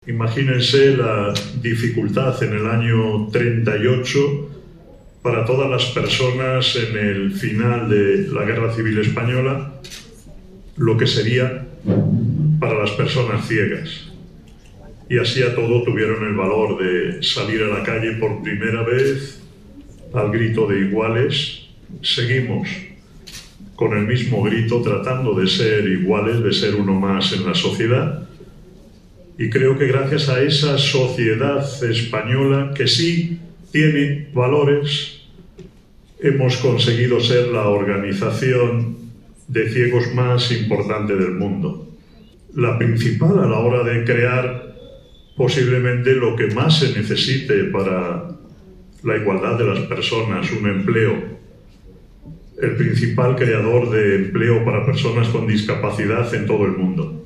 Embajadores, cónsules y consejeros de embajadas participaron en la Embajada de Francia en España en el VI Encuentro Diplomacia para la Inclusión organizado por el Grupo Social ONCE y la Academia de la Diplomacia, bajo el patrocinio del embajador francés en nuestro país, que ostenta la presidencia semestral del Consejo de la UE.